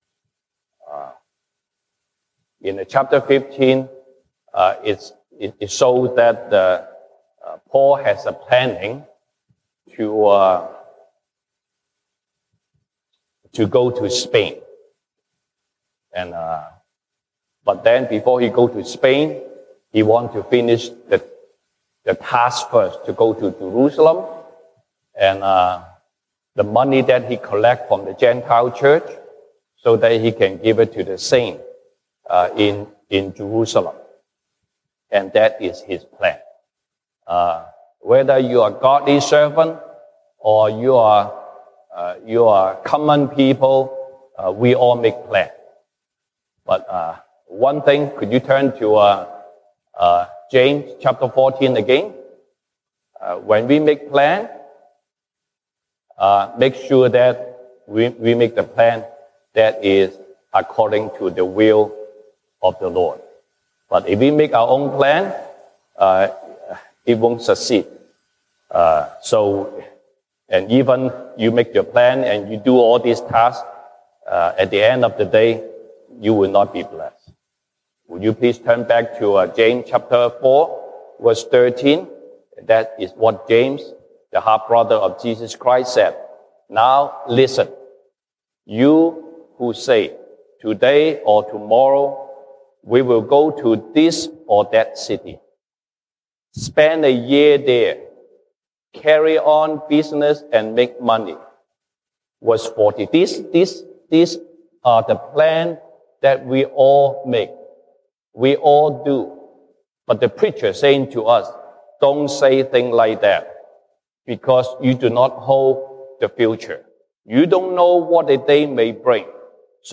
西堂證道 (英語) Sunday Service English: If it is the Lord’s will, we will live and do this or that.
Romans Passage: 羅馬書 Romans 15:23-29 Service Type: 西堂證道 (英語) Sunday Service English Topics